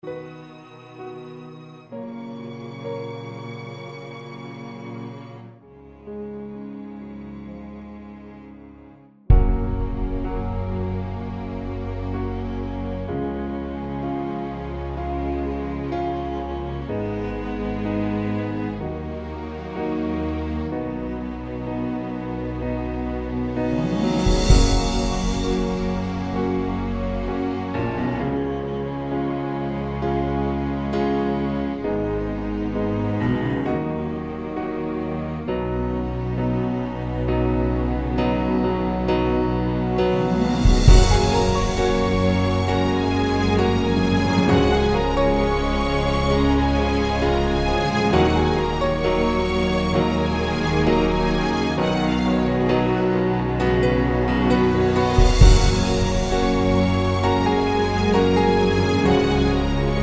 Down 1 Semitone Musicals 3:27 Buy £1.50